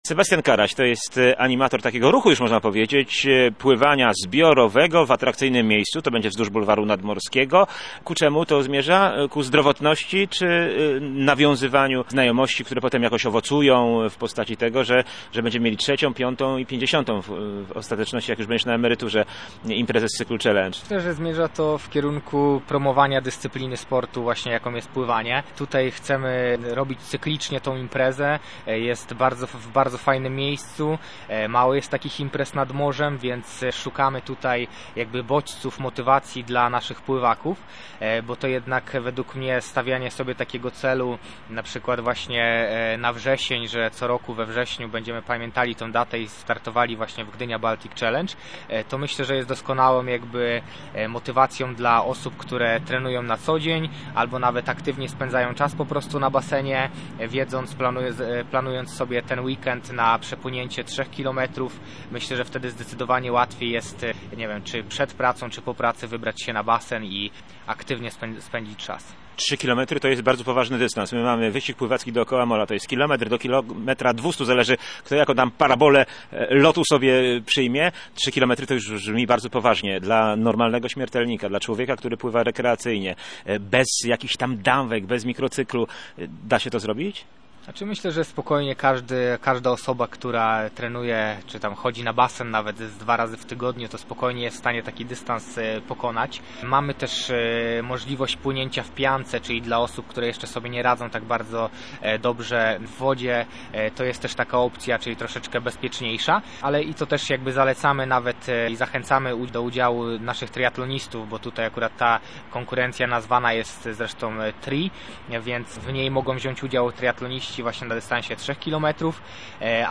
Plan zajęć na 1 września prezentuje w rozmowie z Radiem Gdańsk: